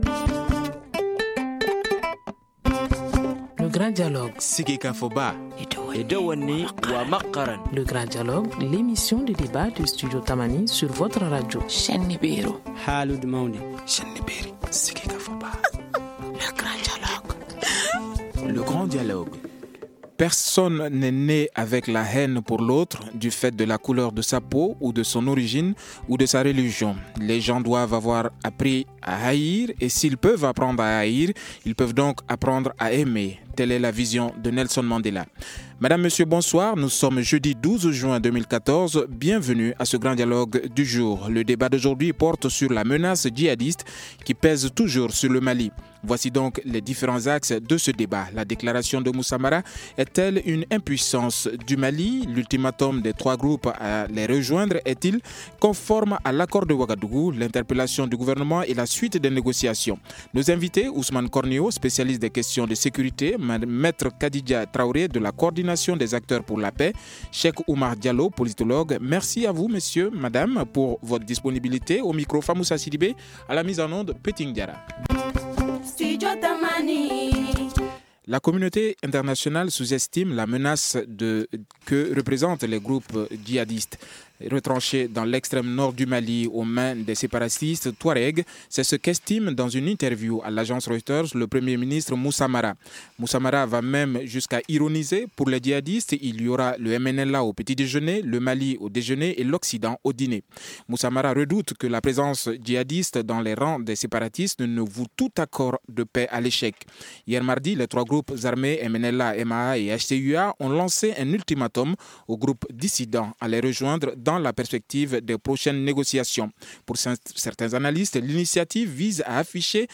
Voici donc les différents axes de ce débat :